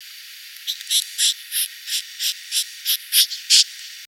Сигналы жуков (Coleoptera) России
Сигналы протеста
Жук издает звуковые сигналы трением последних тергитов брюшка об элитры.